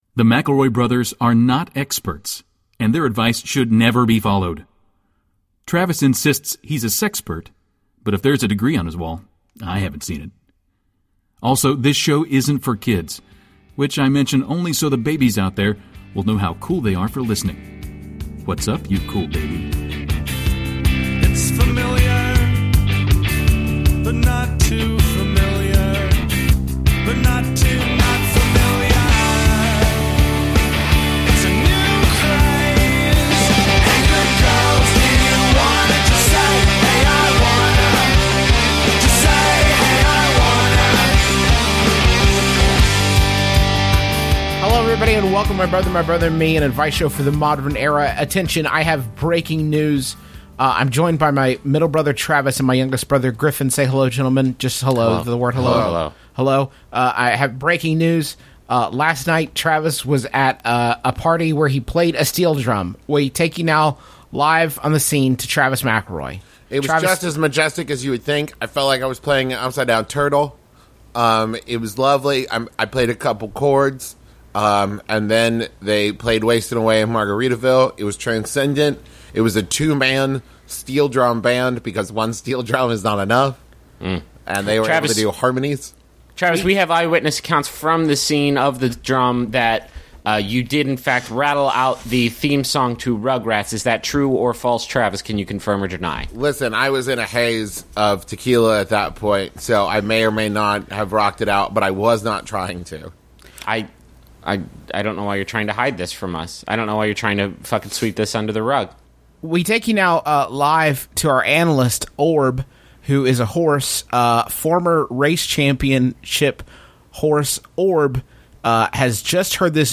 Mbmbam, Mcelroy Brothers, Advice, Justin Mcelroy, Travis Mcelroy, Comedy Advice, Mcelroy, Griffin Mcelroy, Comedy